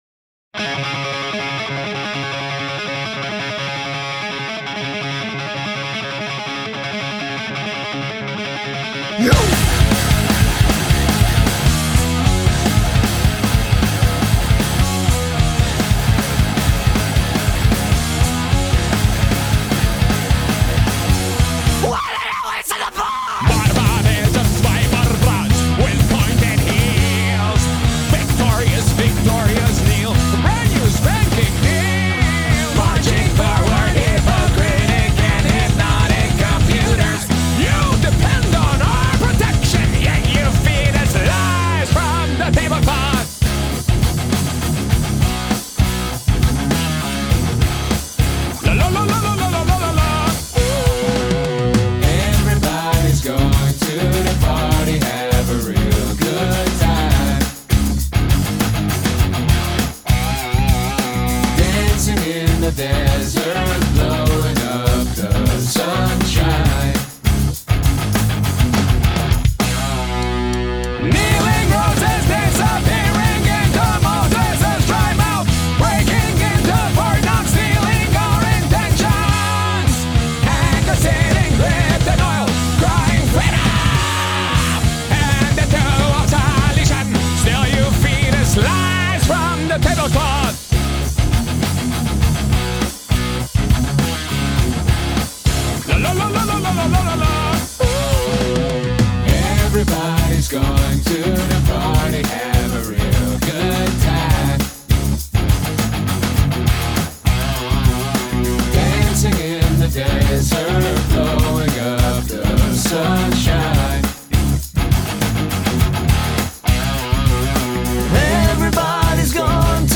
BPM200-320
Audio QualityPerfect (High Quality)